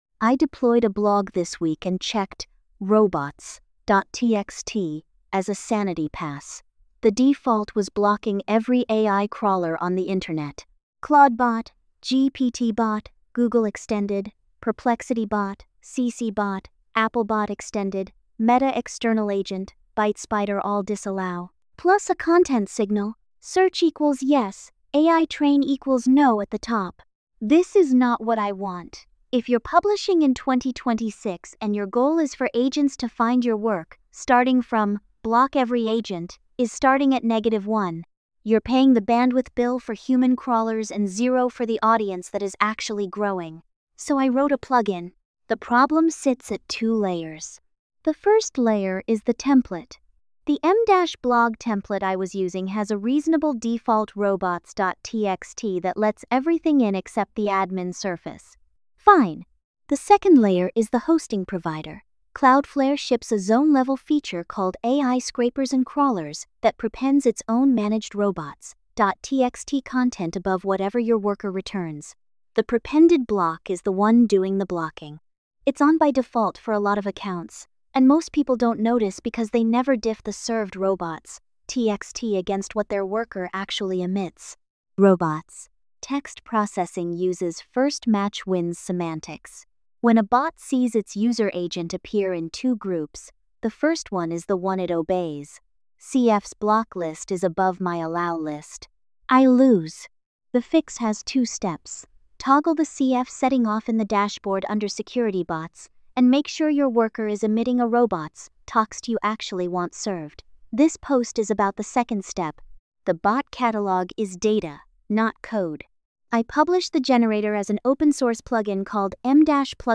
AI-narrated with MiniMax speech-2.8-hd · 7:20.